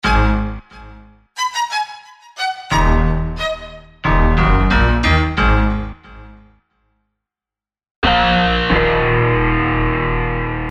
描述：Fifth loop is done with a string hit, heavy piano chords lightly reverbed and an Inferno lead to finish it off Fl 8xxl + Adobe audition 3; use as you feel
标签： 90 bpm Hip Hop Loops Synth Loops 1.79 MB wav Key : Unknown
声道立体声